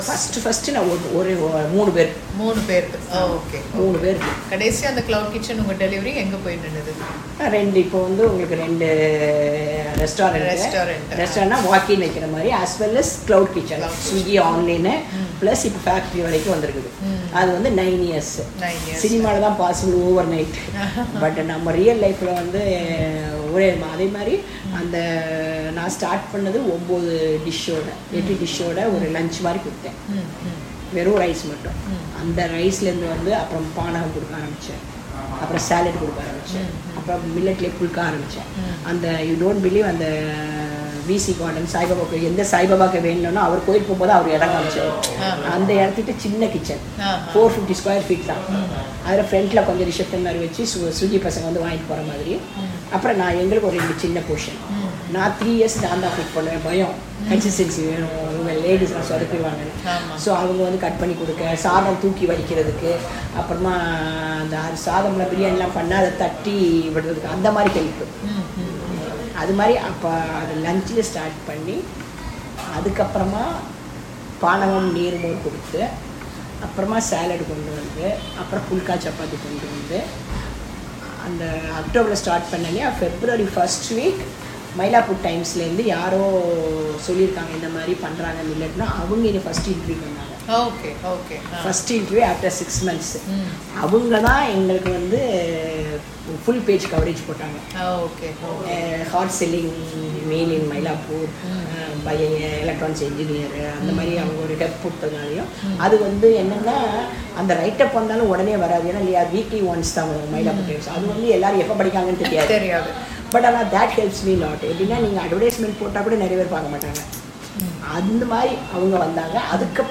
நேர்காணல்கள்